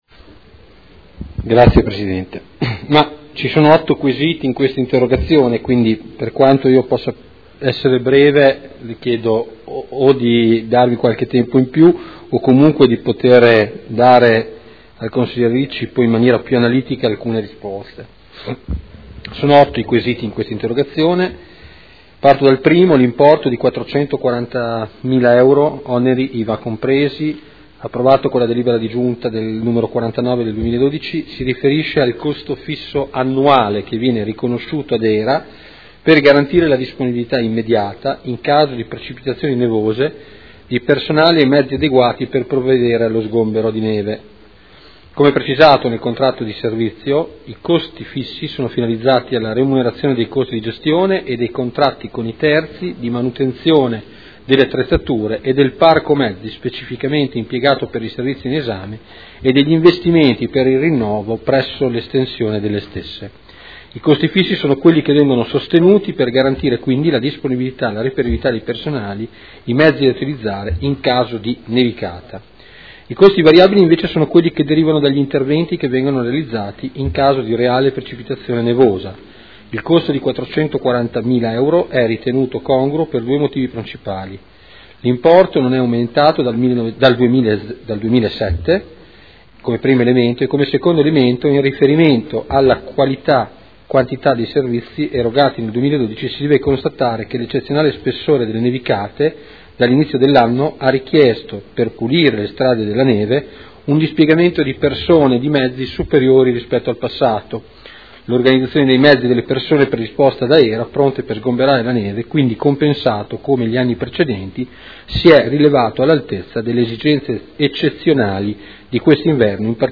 Antonino Marino — Sito Audio Consiglio Comunale
Seduta del 25/06/2012. Risponde a interrogazione del consigliere Ricci (Sinistra per Modena) avente per oggetto: “Costi del servizio neve”